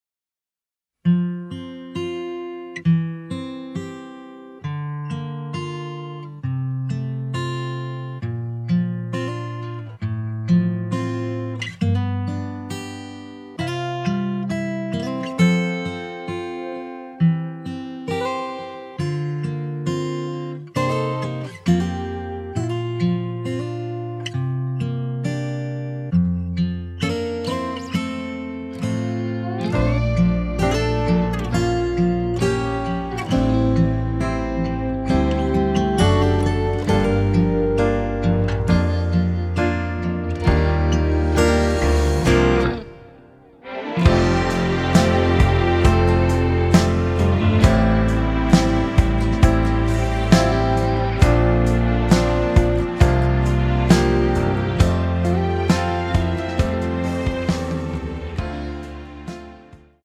” [공식 음원 MR] 입니다.
앞부분30초, 뒷부분30초씩 편집해서 올려 드리고 있습니다.